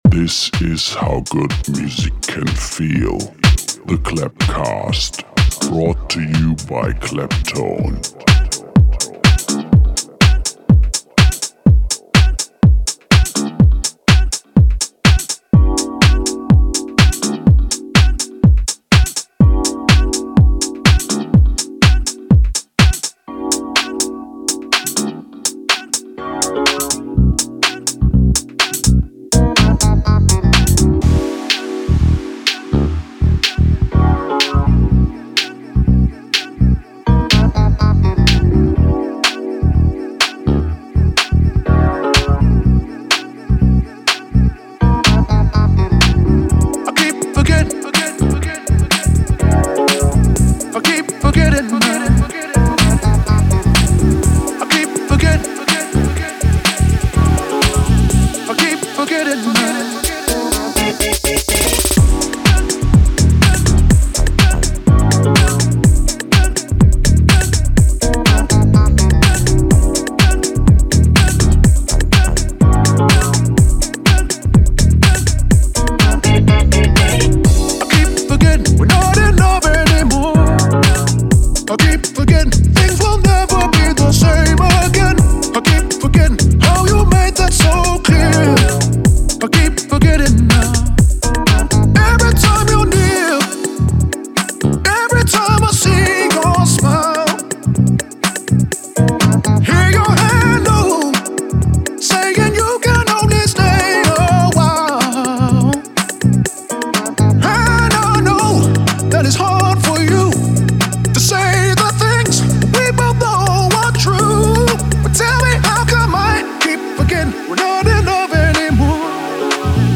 with a mix designed to enchant outside of the club.